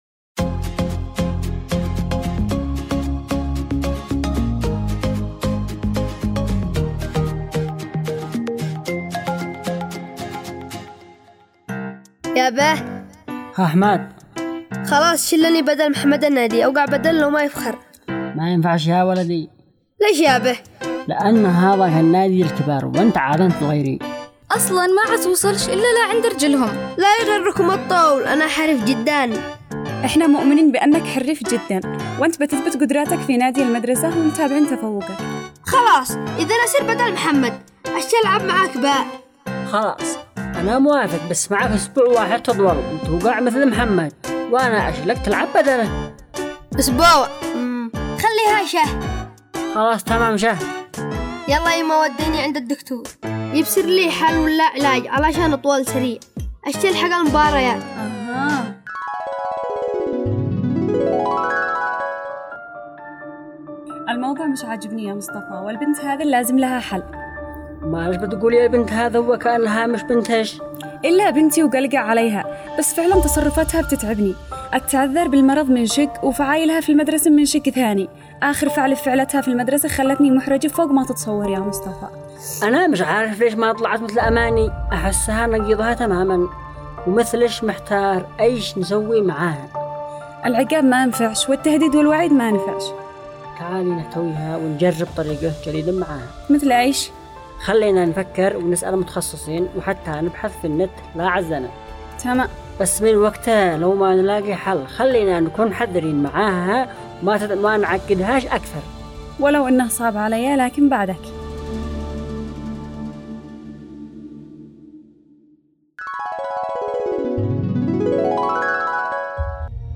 دراما رمضانية - عائلة مصطفى